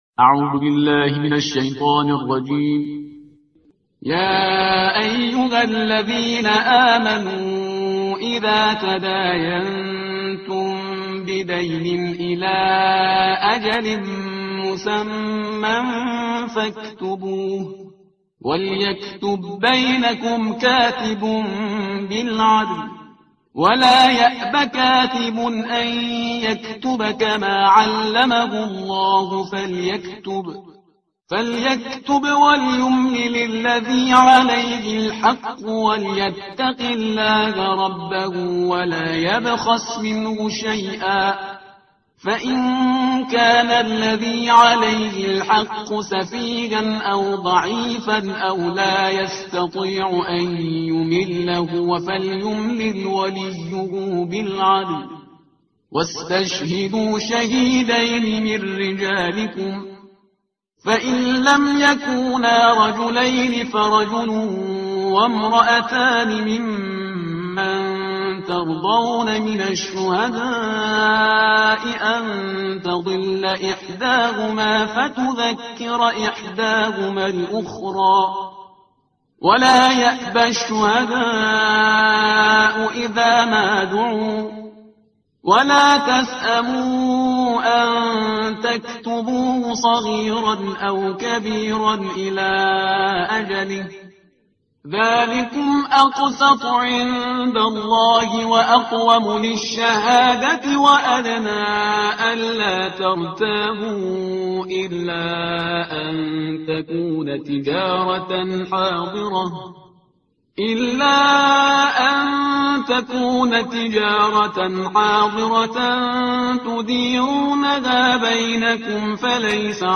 ترتیل صفحه 48 قرآن با صدای شهریار پرهیزگار